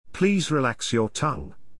ﾌﾟﾘｰｽﾞ ﾘﾗｯｸｽ ﾕｱ ﾀﾝｸﾞ